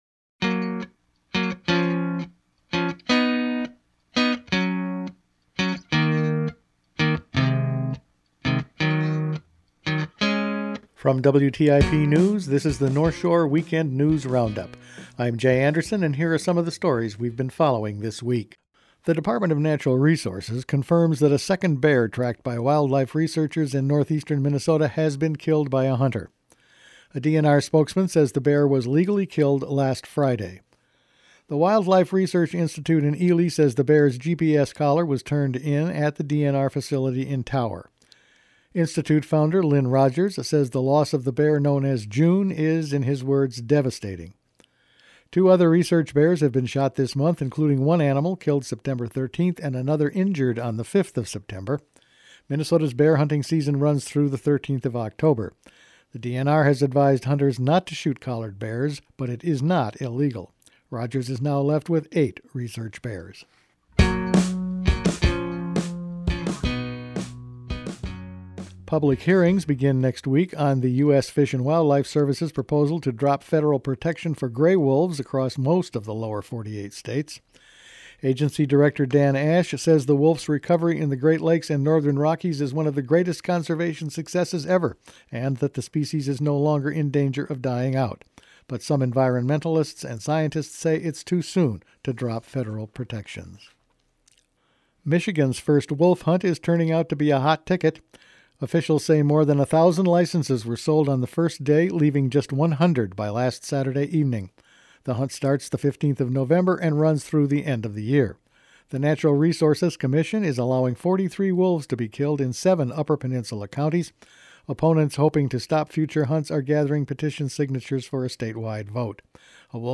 Weekend News Roundup for October 5
Each week the WTIP news staff puts together a roundup of the news over the past five days. A group of Native Americans protest the proposed tar sands pipeline, wolves and bears are back in the news, state officials visit a closed Wisconsin copper mine, and more …all in this week’s news.